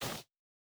Bare Step Snow Hard E.wav